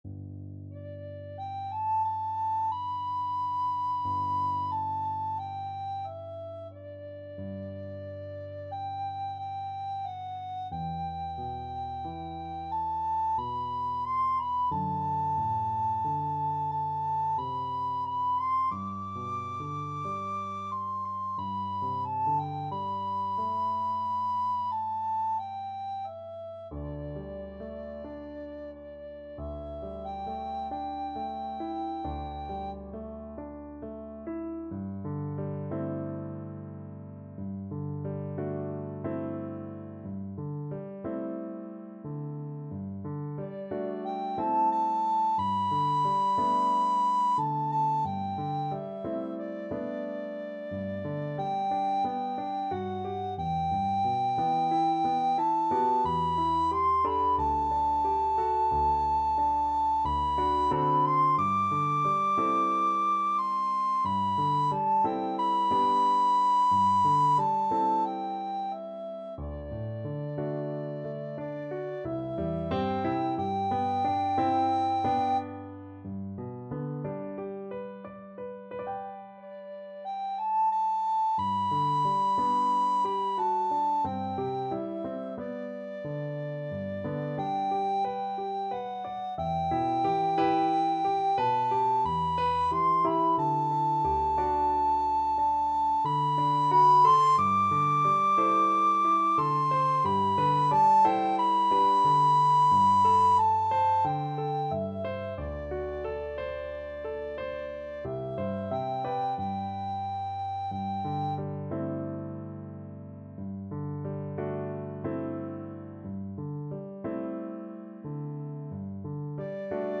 Free Sheet music for Soprano (Descant) Recorder
4/4 (View more 4/4 Music)
Andante cantabile = c. 90